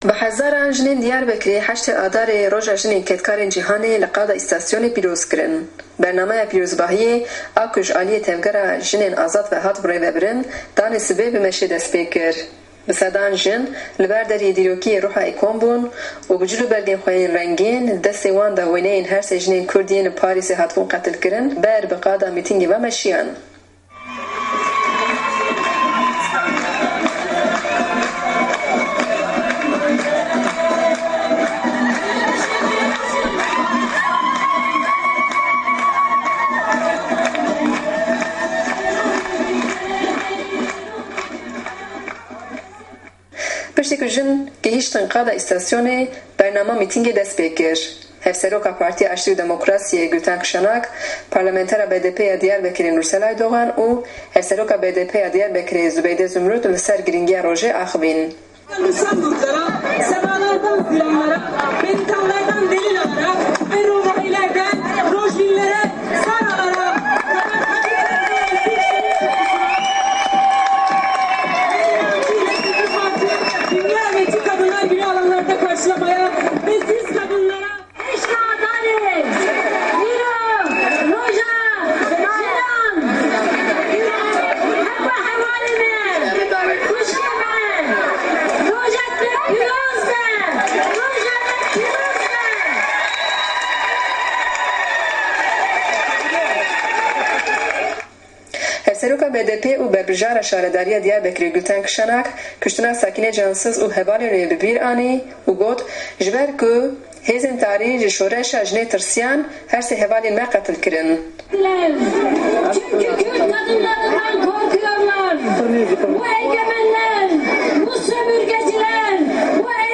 هه‌زاران ژن له‌ دیاربه‌کر له‌ مه‌یدانی ستاسیۆن ئاماده‌ بوون له‌ ڕێوڕه‌سمێکدا به‌بۆنه‌ی ڕۆژی جیهانی ژنانه‌وه‌.